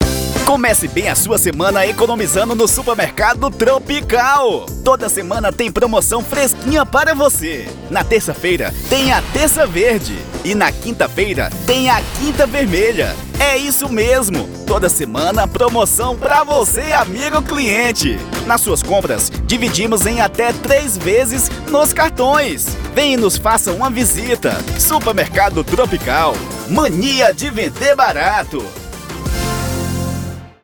LOCUÇÃO VAREJO: